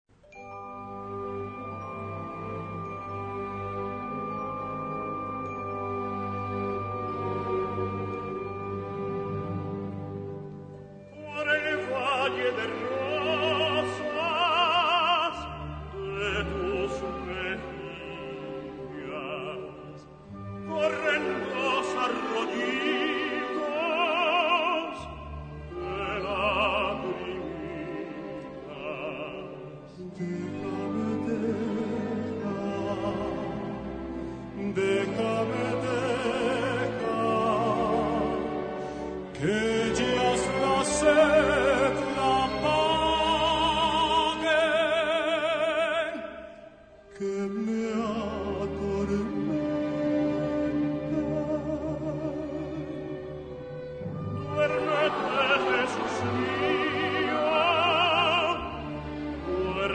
key: G-major